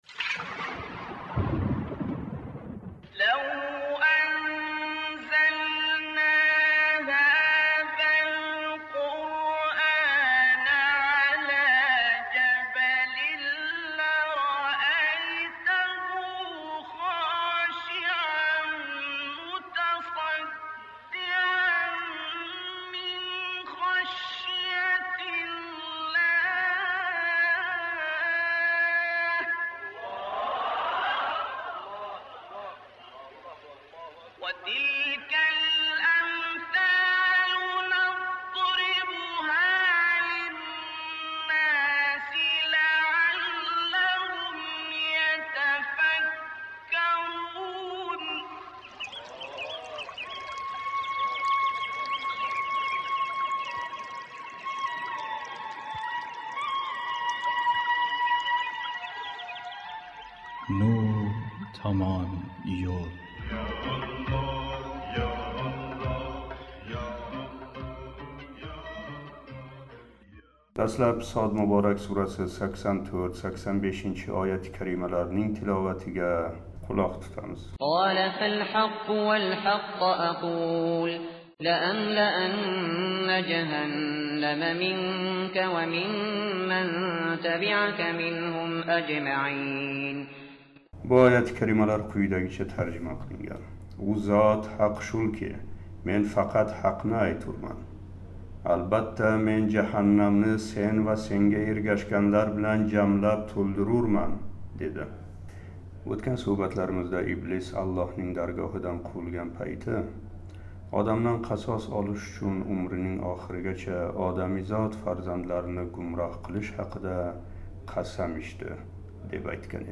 Энди “Сод” муборак сураси 86-88- ояти карималарининг тиловатига қулоқ тутамиз: